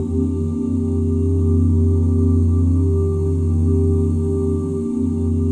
OOH F MIN9.wav